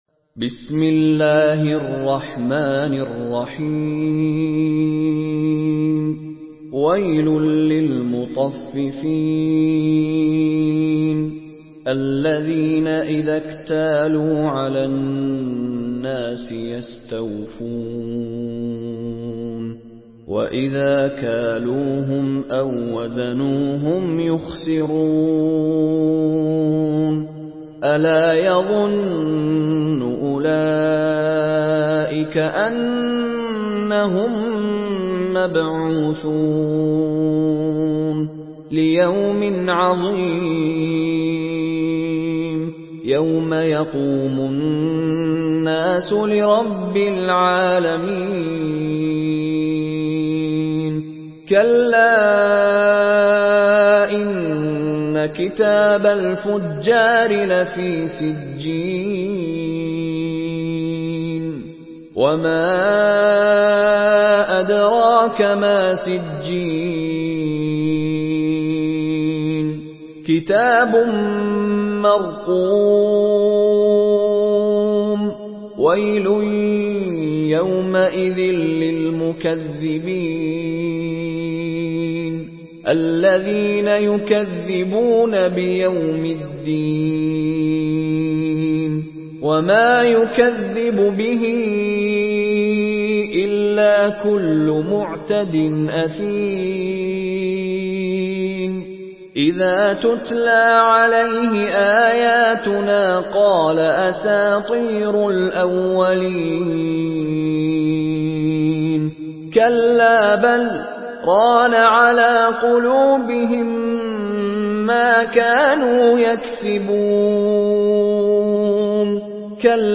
Surah আল-মুতাফফিফীন MP3 by Mishary Rashid Alafasy in Hafs An Asim narration.
Murattal Hafs An Asim